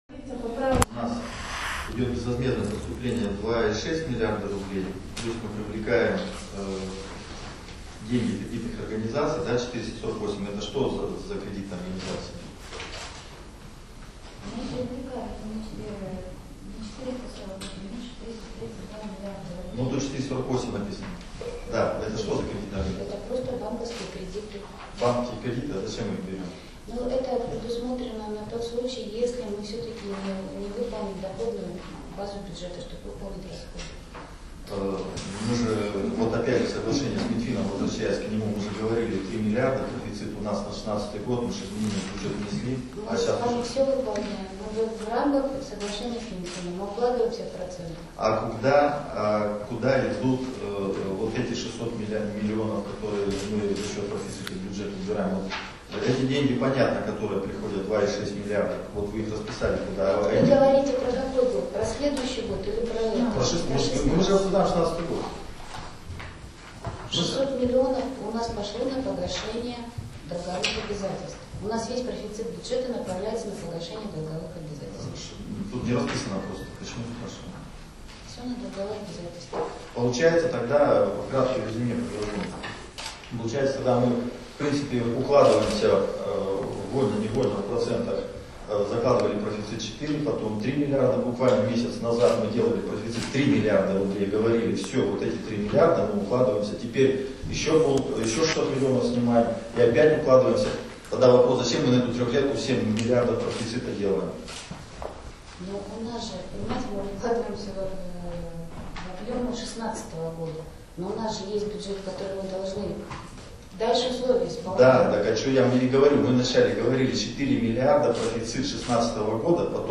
Выступление руководителя фракции Виктора Леухина на заседании комитета ЗСО по экономической политике и собственности 06.11.2016г.